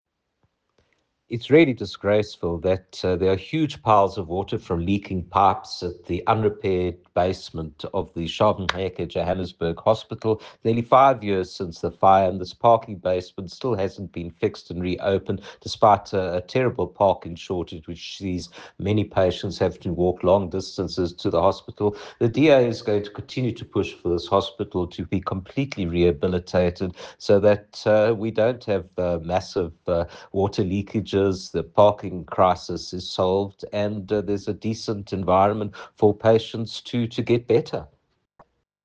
soundbite by Dr Jack Bloom MPL.